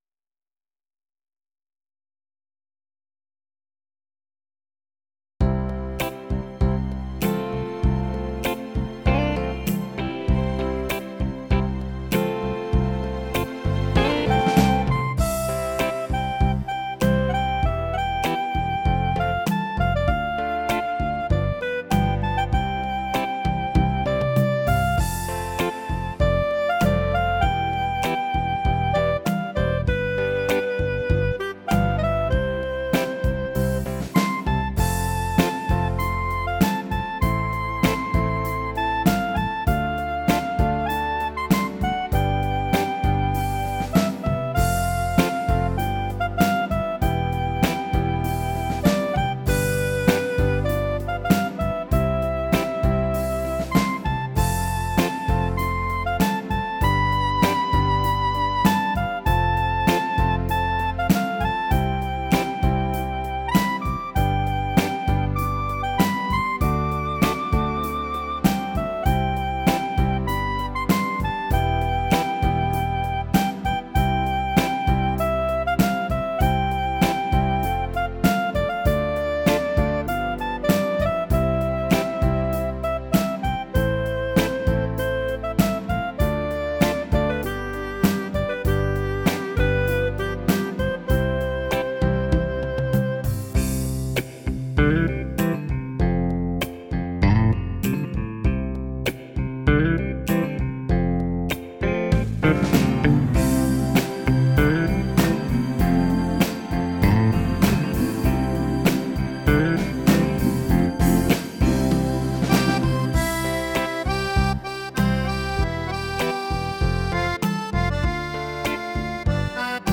Arrangement & Keyboard